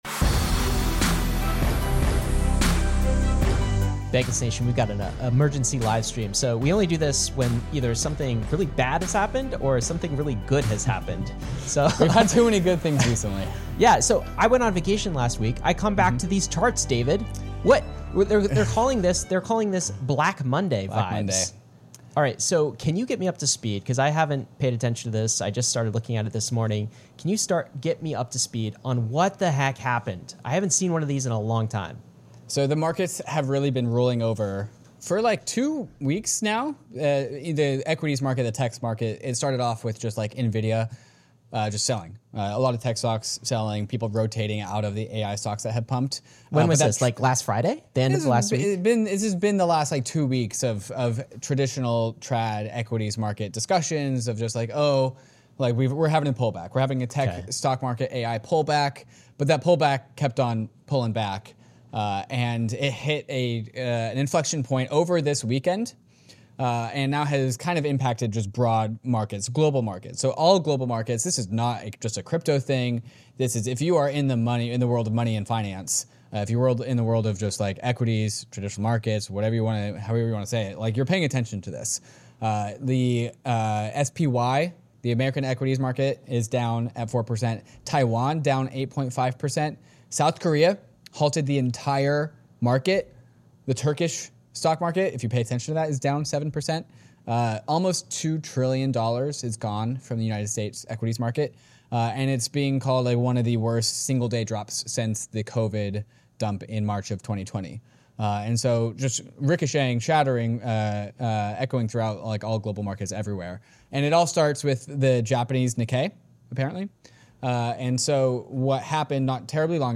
Podcast: Bankless Published On: Mon Aug 05 2024 Description: What on earth is going on with markets around the world?! We break it all down live on stream. What just happened, what does it mean, and where we go from here.